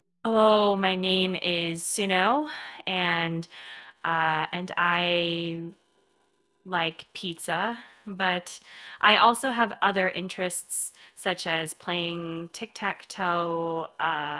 🔊 Text-Prompted Generative Audio Model